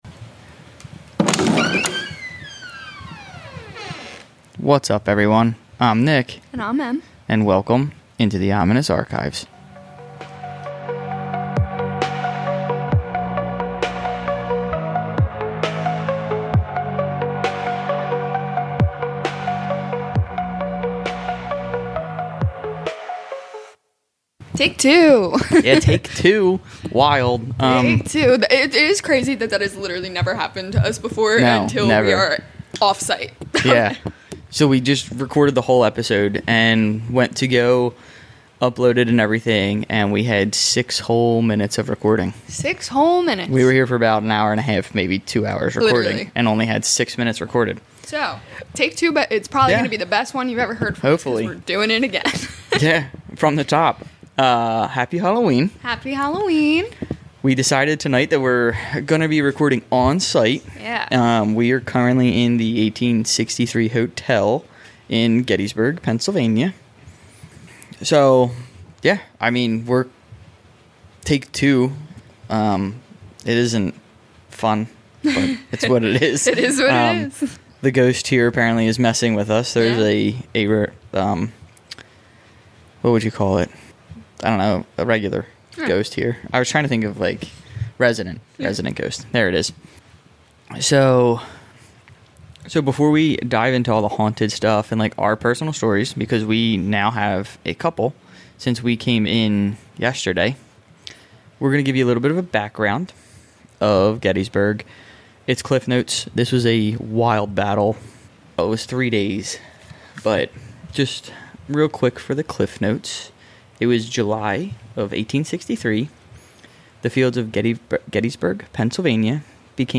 Recording straight from Gettysburg, we explore the lingering cries of battle and restless spirits haunting this historic ground. 📜 A BLOODY BACKDROP The 1863 Battle of Gettysburg left over 50,000 casualties.